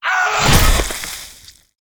PlayerDeath.ogg